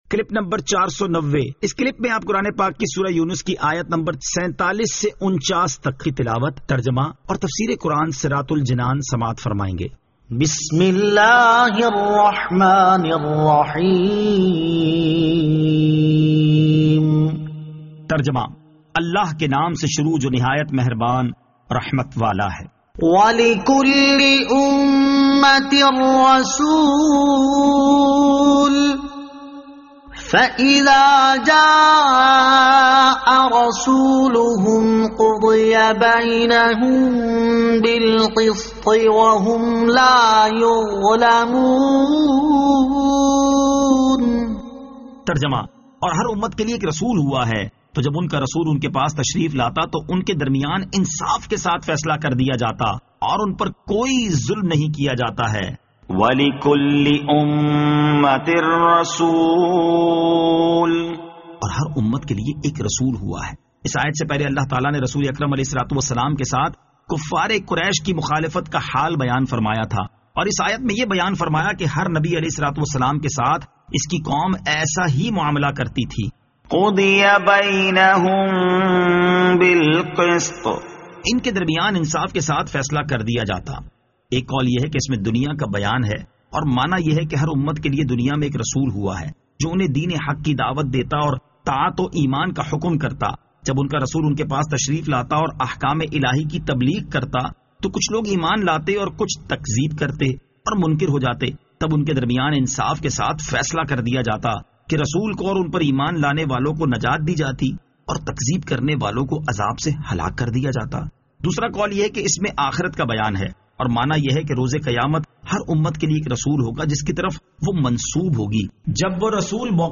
Surah Yunus Ayat 47 To 49 Tilawat , Tarjama , Tafseer